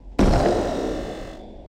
explosion_2.wav